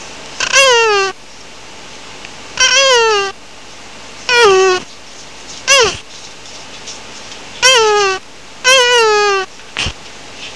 TORTOISE CALL